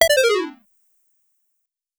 failure-notification.wav